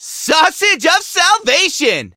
doug_ulti_vo_03.ogg